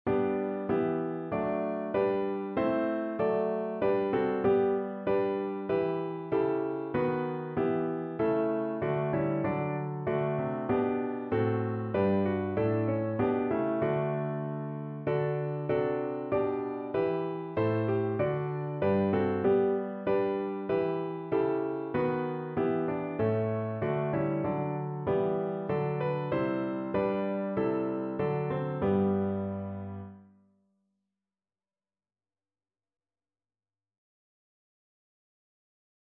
Notensatz 1 (4 Stimmen gemischt)